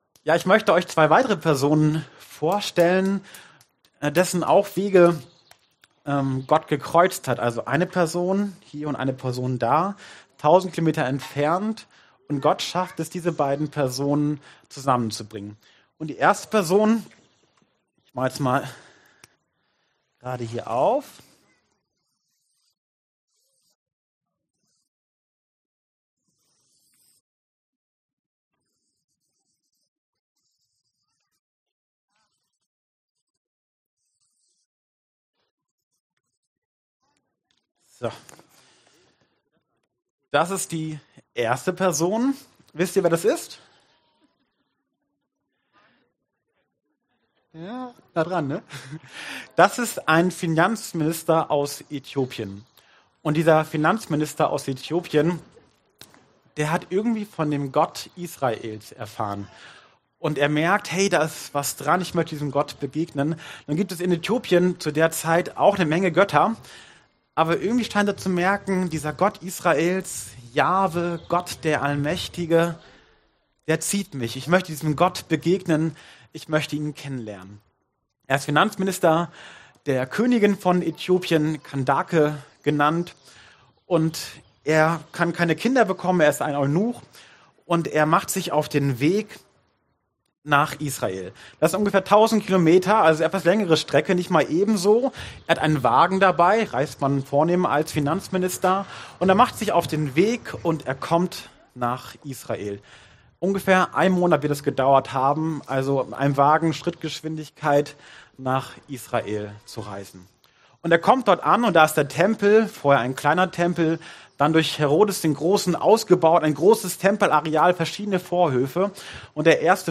Taufgottesdienst – Philippus und der Kämmerer
Passage: Apostelgeschichte 8, 26ff Dienstart: Predigt « Jünger für die Gemeinschaft Jüngerschaft